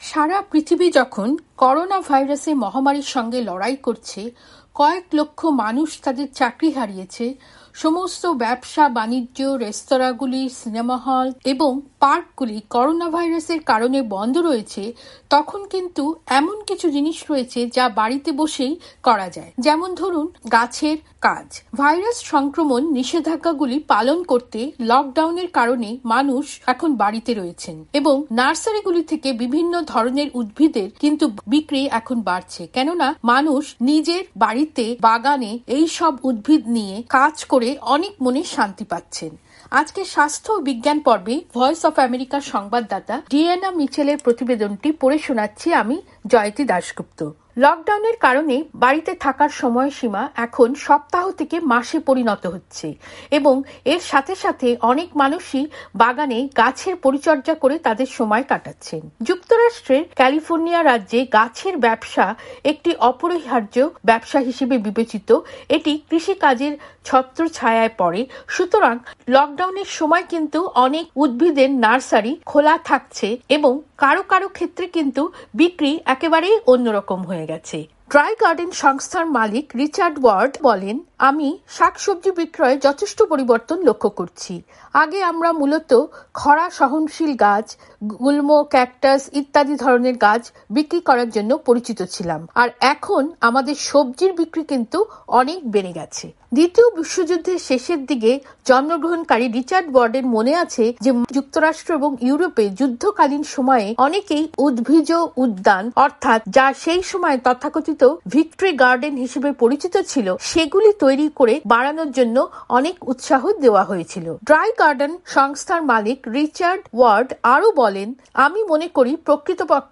প্রতিবেদনটি পড়ে শোনাচ্ছেন